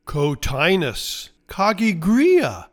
Pronounciation:
Co-TIE-nus cog-gee-GREE-uh